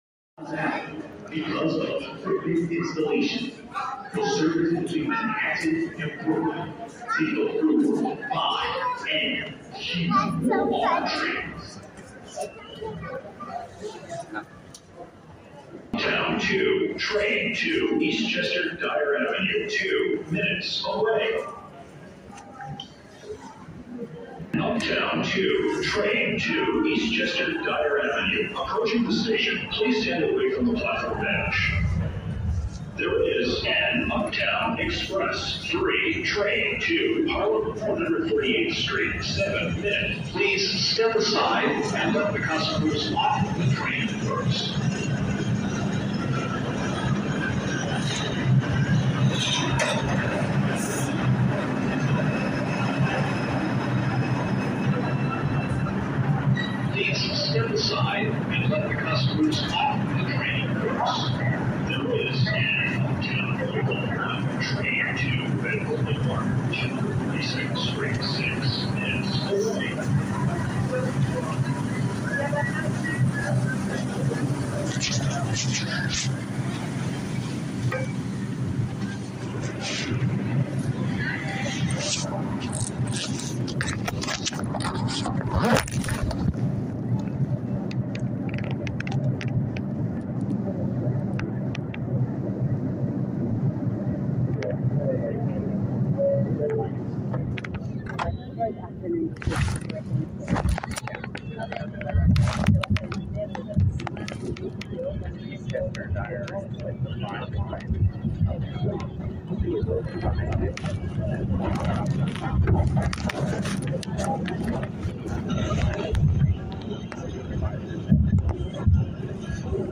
audio recording of subway sounds from November 12, 2023.